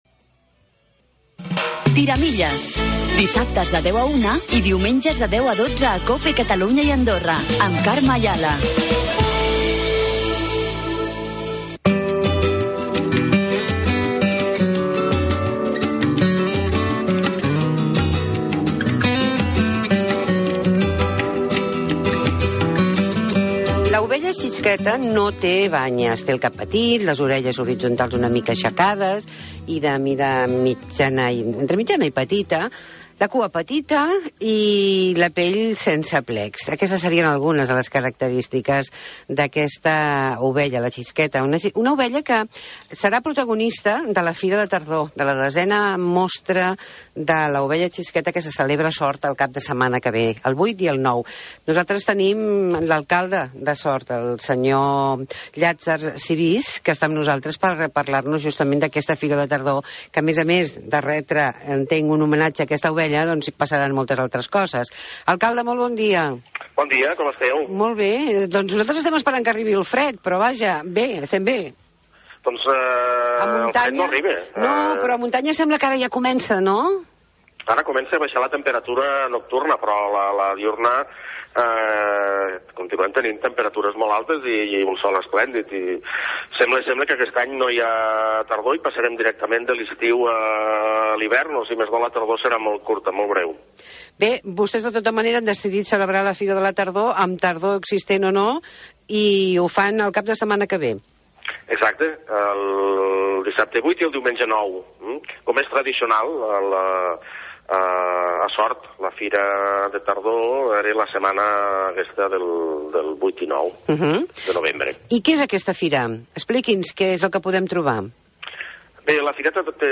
Des de l'Agencia Catalana de Turisme ens proposen fer una ullada a la Fira de Tardor de Sort. Parlem amb l'alcalde Llátzer Subís